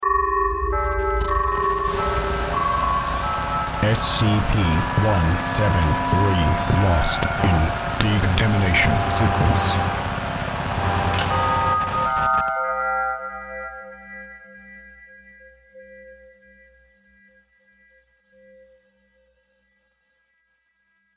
SCP death by decontamination